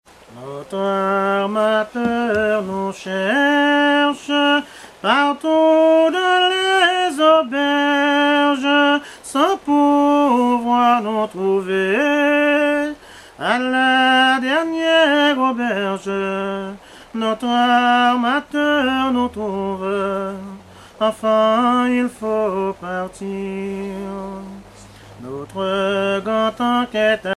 chansons anciennes recueillies en Guadeloupe
Pièce musicale inédite